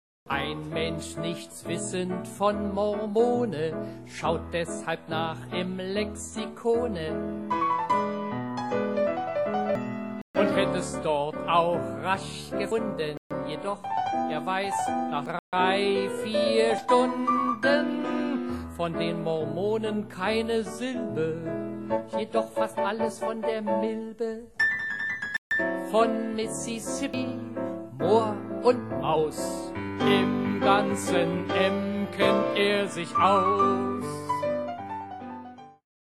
Heiteres Soloprogramm am Klavier